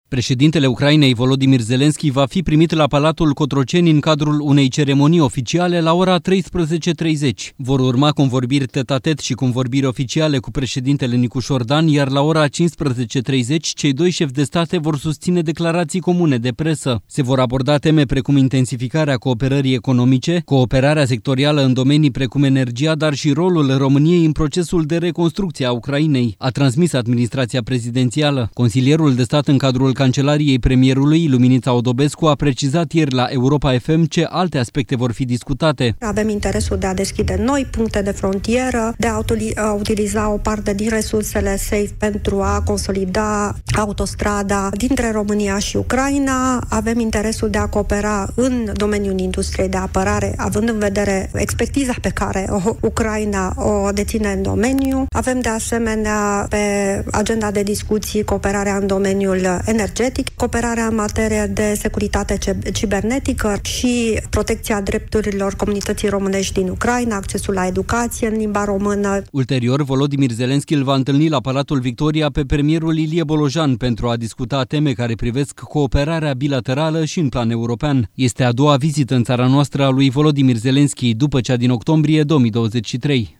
Consilierul de stat Luminița Odobescu a precizat miercuri, la emisiunea „Piața Victoriei”, la Europa FM, ce alte aspecte ce vor fi discutate.